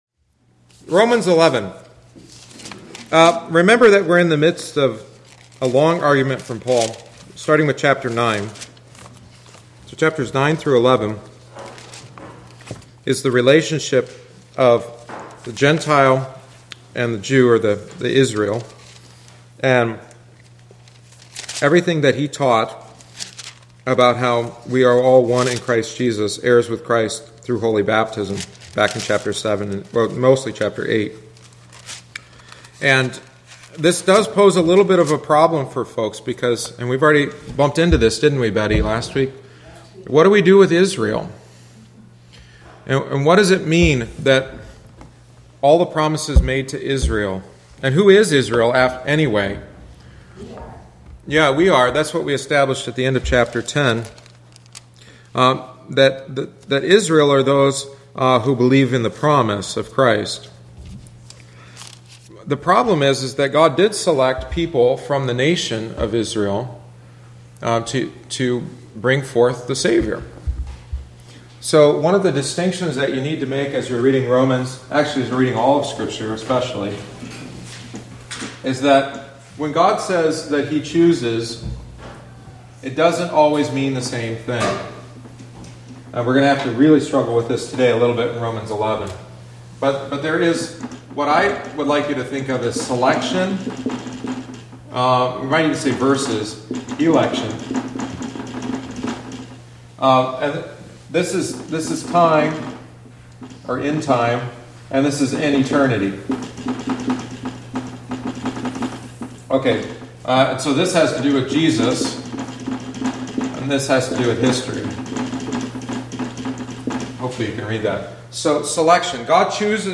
The following is the twenty-fifth week’s lesson.